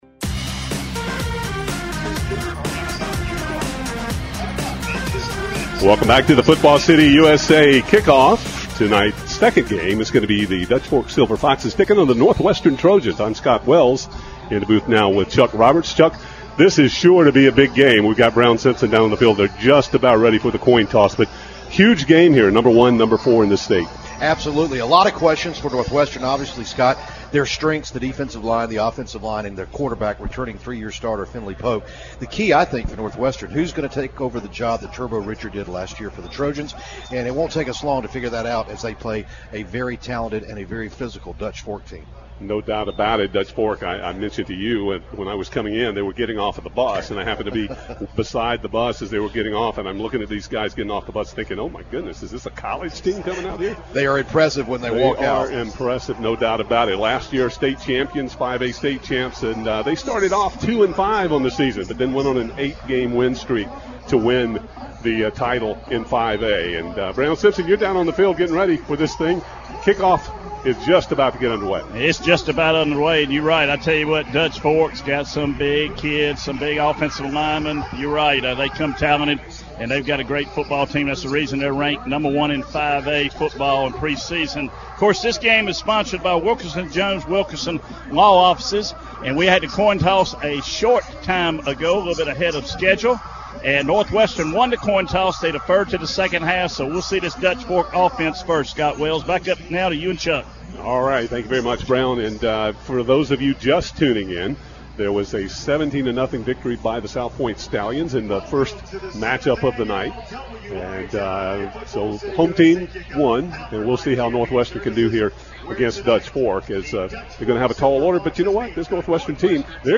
have the call for game number two of the WRHI Football City U.S.A. Kick-Off